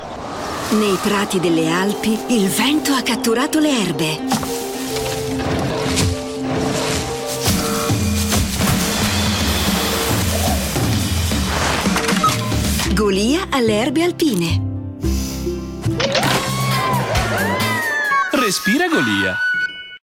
Ironico